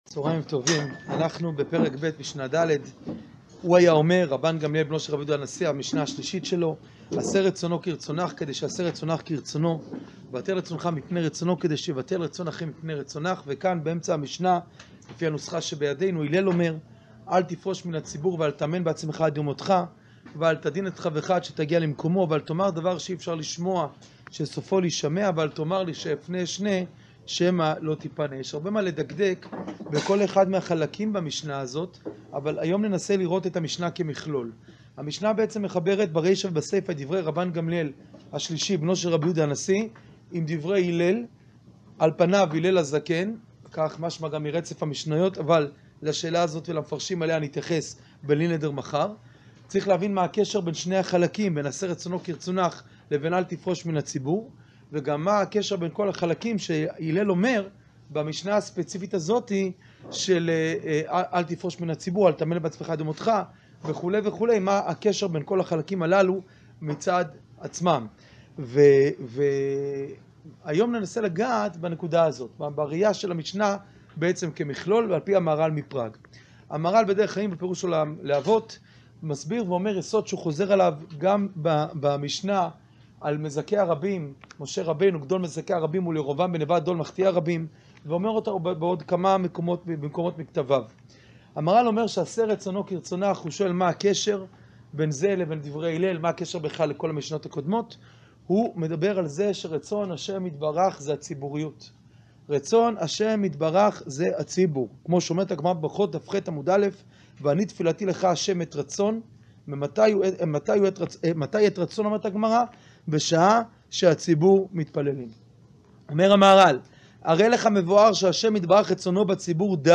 שיעור פרק ב משנה ד